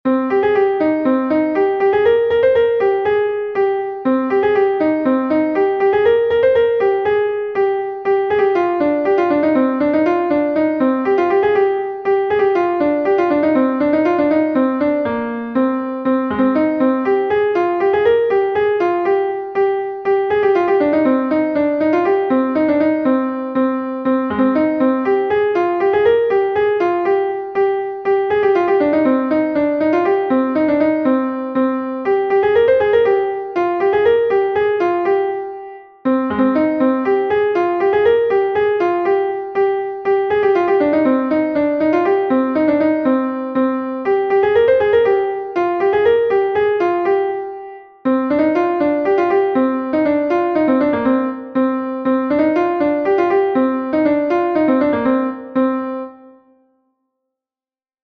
Gavotenn Er Gemene Bro-Bourled I is a Gavotte from Brittany